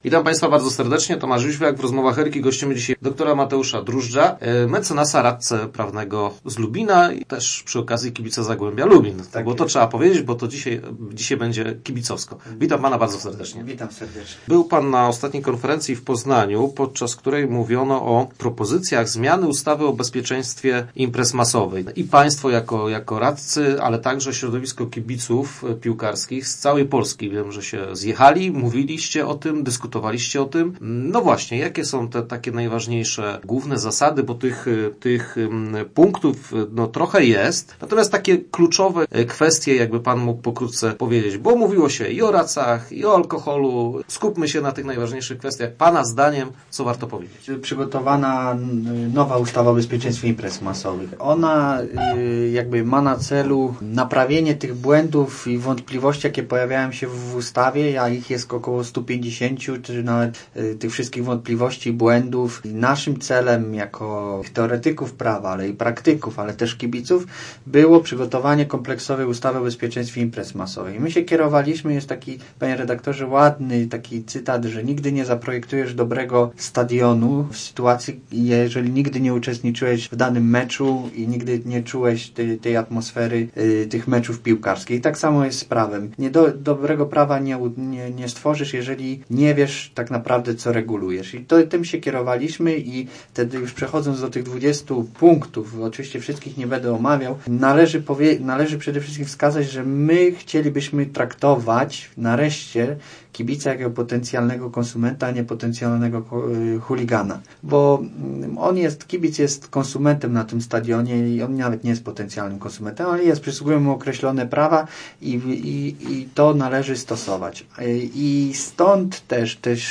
Start arrow Rozmowy Elki arrow Kibic to konsument